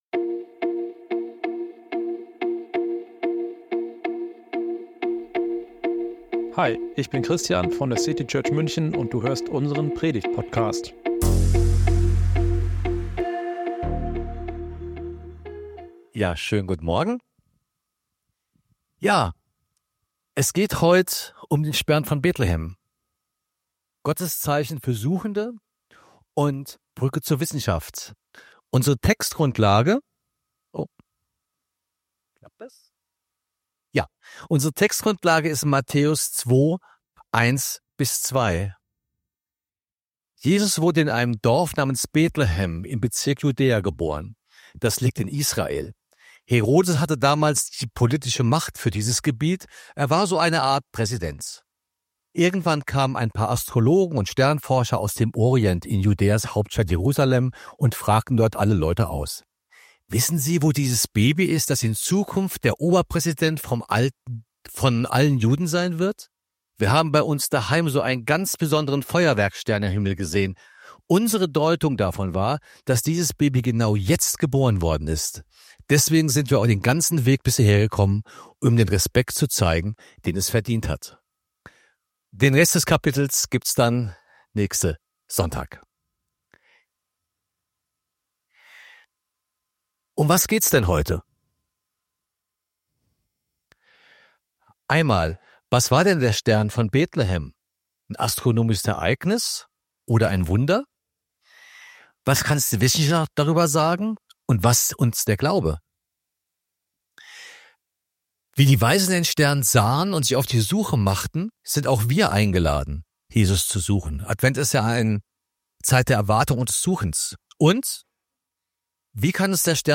Wie kann uns der Stern von Bethlehem heute helfen, Gott besser zu verstehen? Wir wollen in diesem Gottesdienst gemeinsam staunen!